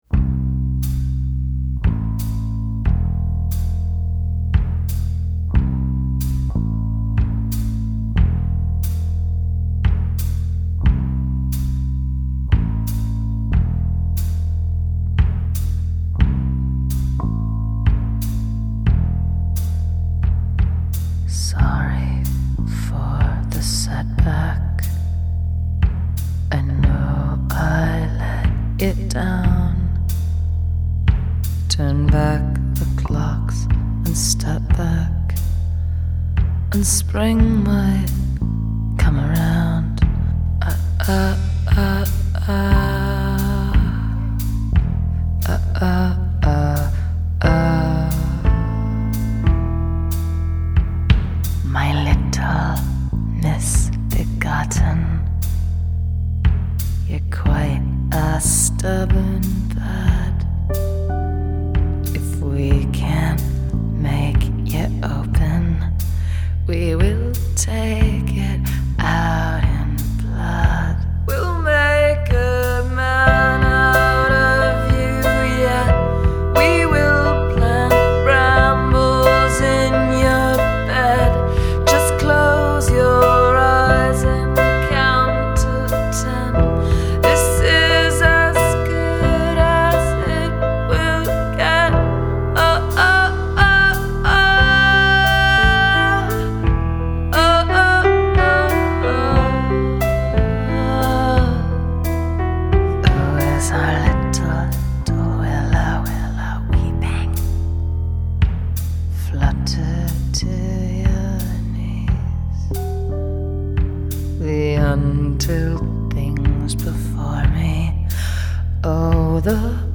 lettere creepy og dampende ballade om hagearbeid og sex